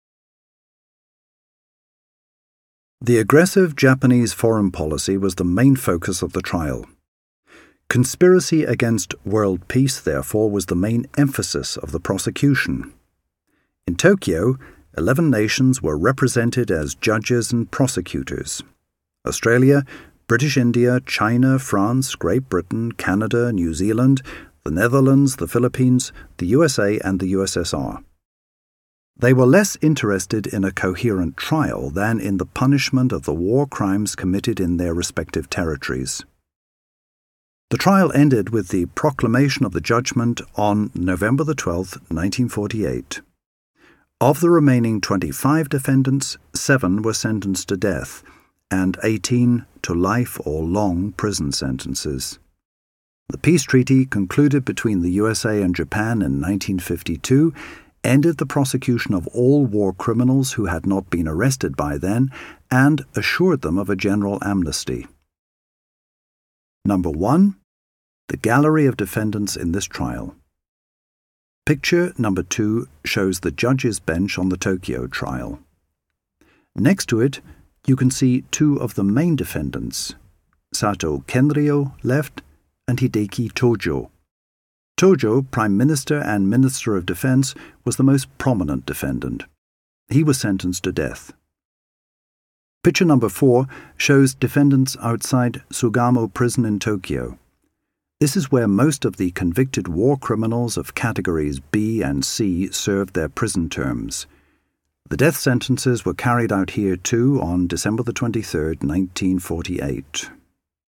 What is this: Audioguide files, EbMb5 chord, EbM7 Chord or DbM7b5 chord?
Audioguide files